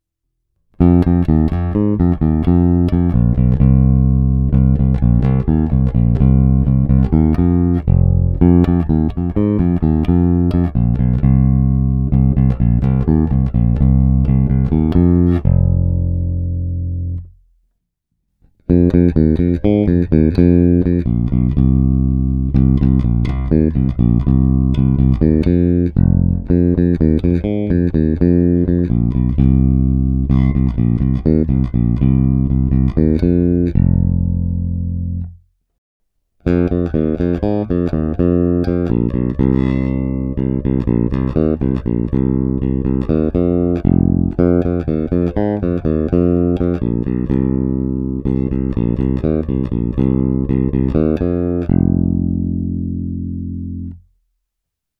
Ukázky jsou nahrány rovnou do zvukové karty a jen normalizovány.